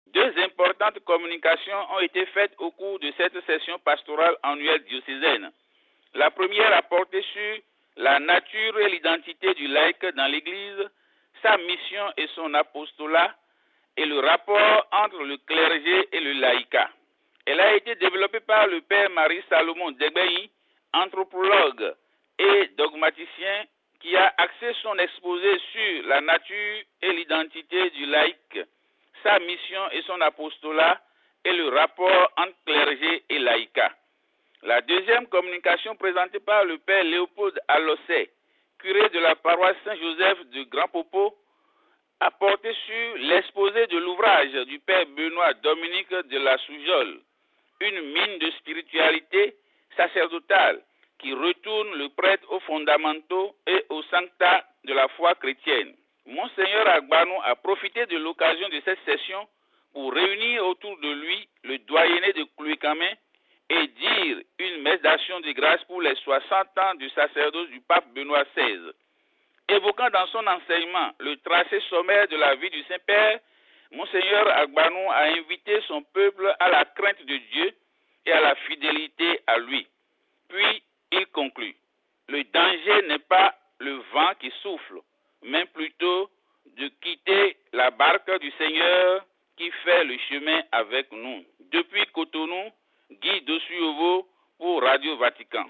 Du 25 au 30 juin dernier s'est tenue à Lokossa une session pastorale autour du thème "la formation pastorale des laïcs", qui a regroupé les prêtres du diocèse autour de leur évêque, Mgr Victor Agbanou, au Grand Séminaire de Tchanvedji. Notre correspondant local